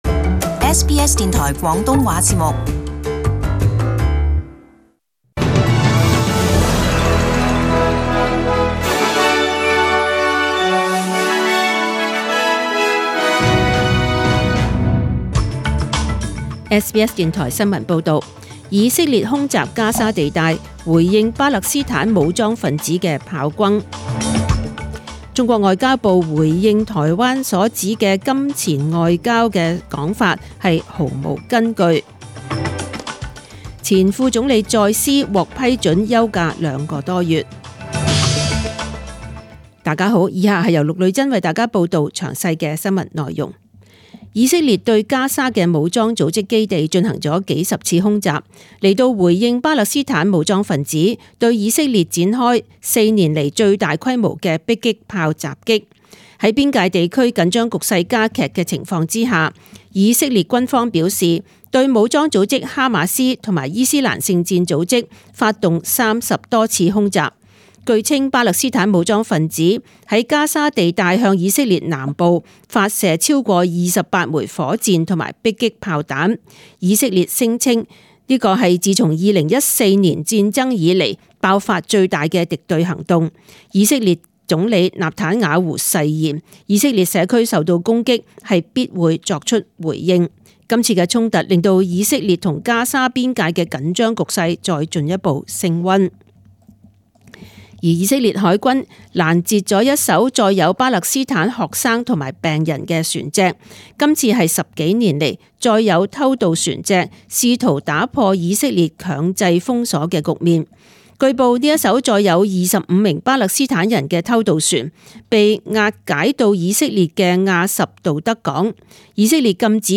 请收听本台为大家准备的详尽早晨新闻。